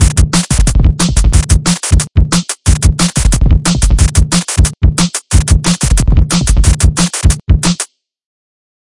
它同时又如此独特和古怪。
Tag: 踢腿 军鼓